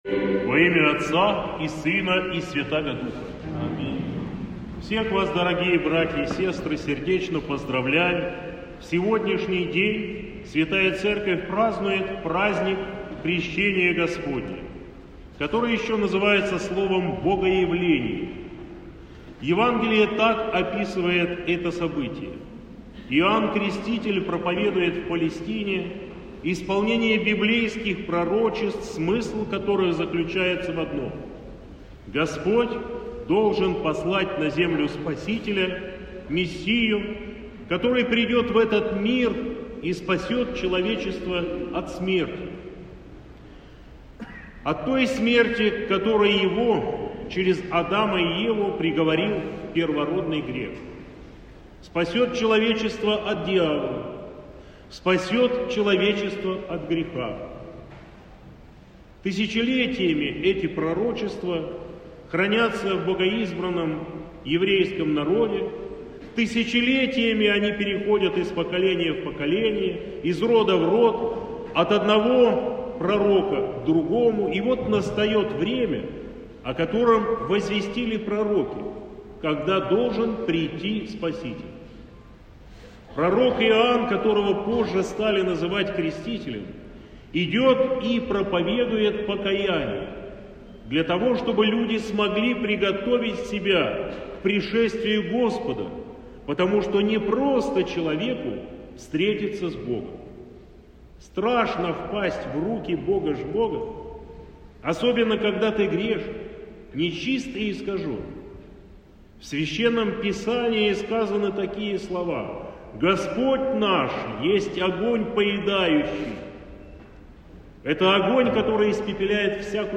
Проповедь